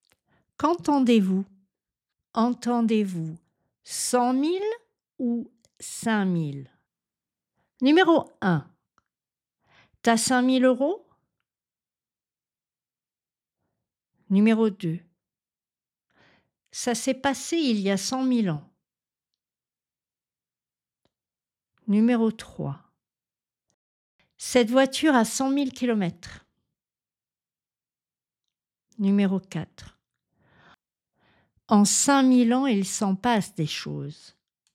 Quand le son /k/ n’est pas prononcé dans 5 000, si l’on ne dispose pas du contexte, il peut être difficile de savoir s’il s’agit de 5 000 ou de 100 000.
Seule la voyelle nasale diffère !